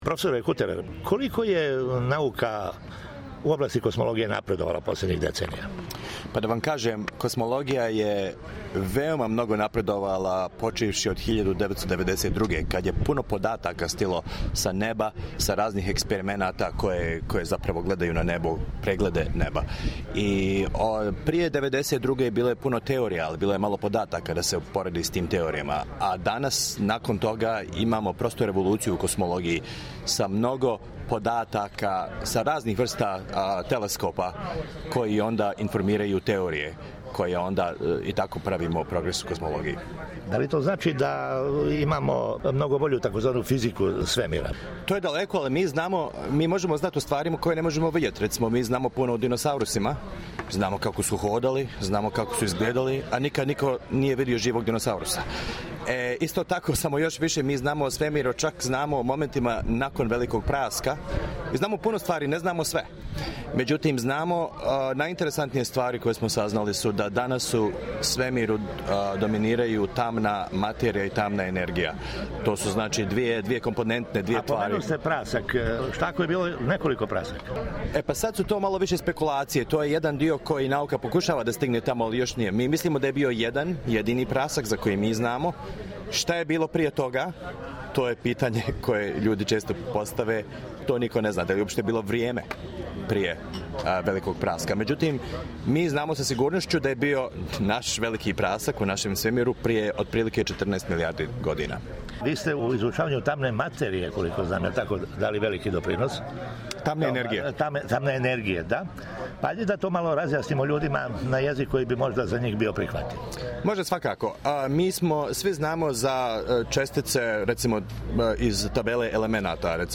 разговарао је са професором